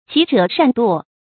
騎者善墮 注音： ㄑㄧˊ ㄓㄜˇ ㄕㄢˋ ㄉㄨㄛˋ 讀音讀法： 意思解釋： 慣于騎馬的人常常會從馬上摔下來。